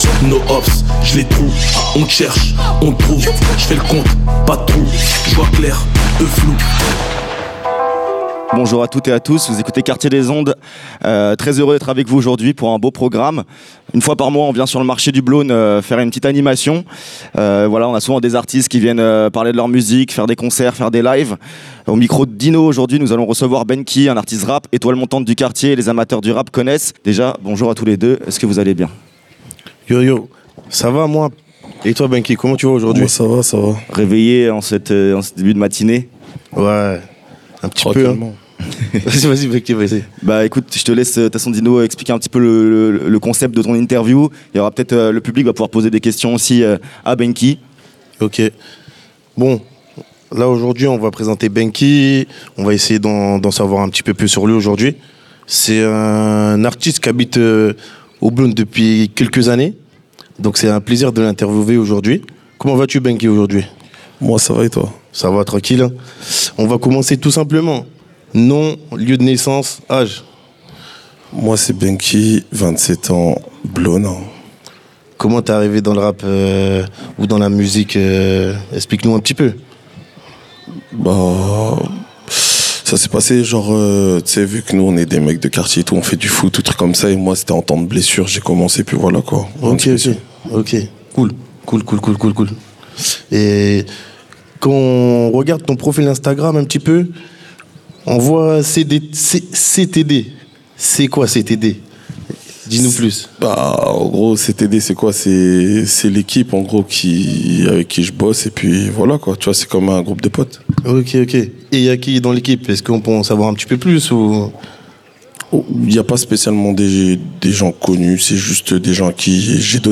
Episode enregistrée en direct du marché du Blosne dans le cadre de l'émission "Marché du Blosne (EP03) - Quartier des Ondes X 808 Bloom" sonorisée sur le marché.